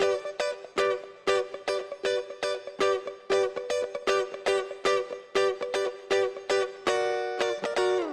12 Guitar PT4.wav